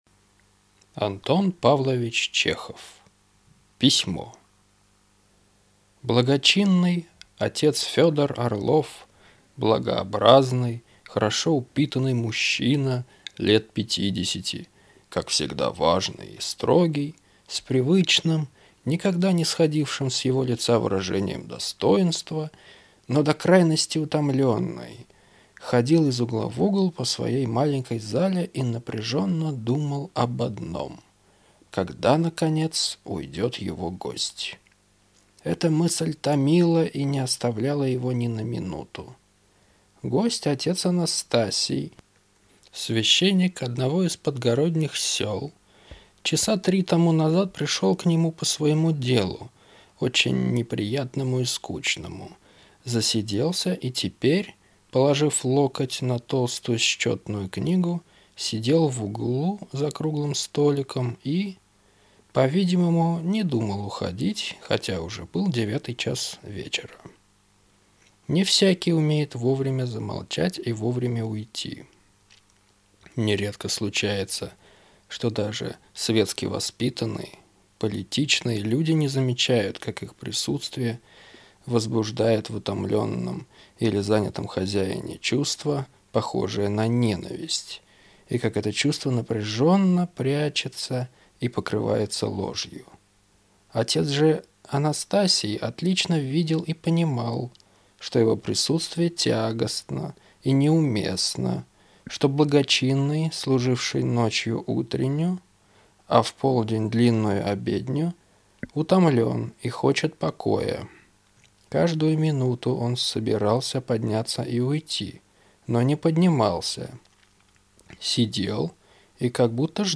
Аудиокнига Письмо | Библиотека аудиокниг